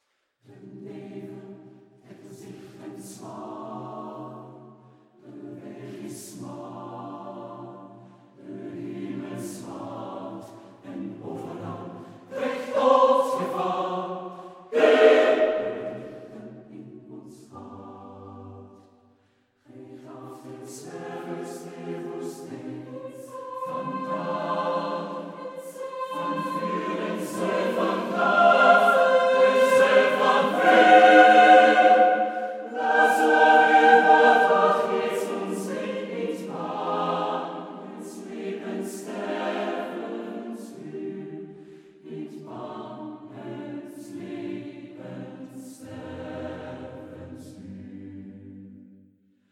Wiener Vokalensemble